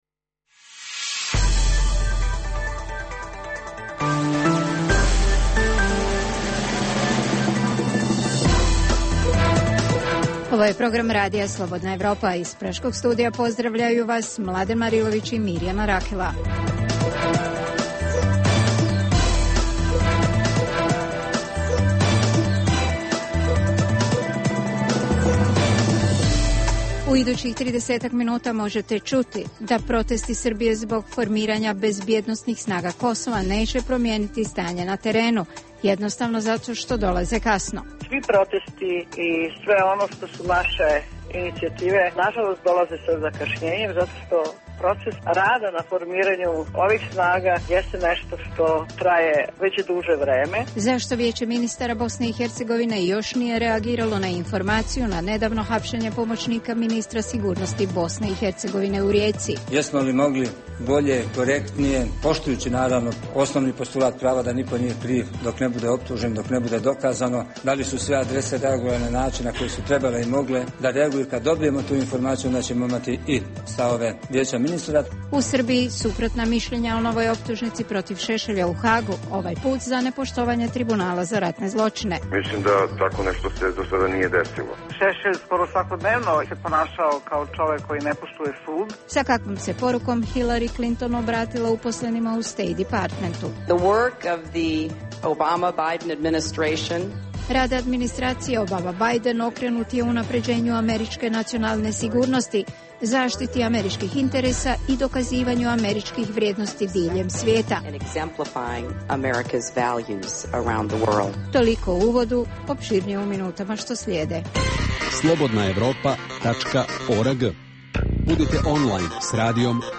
Možete čuti i razgovor sa književnikom Vladimirom Pištalom, dobitnikom nagrade "NIN"-a za roman " Tesla, portret među maskama".